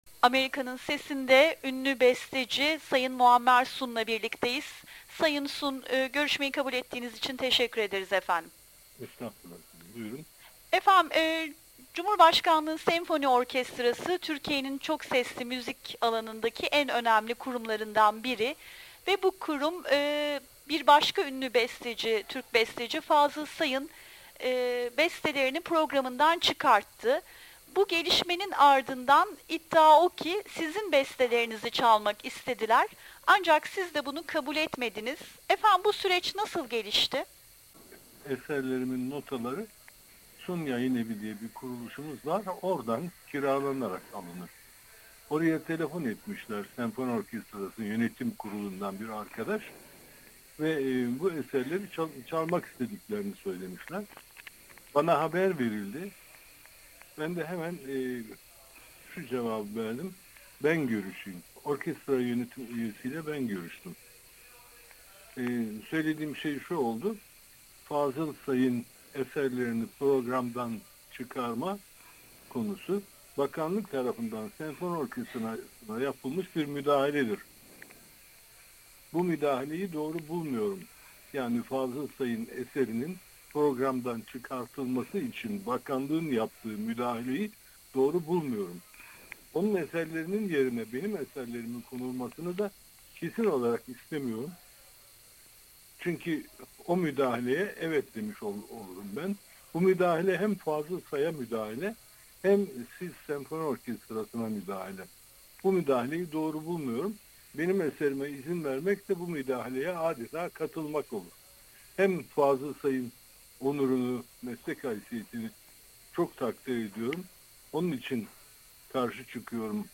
Muammer Sun ile Söyleşi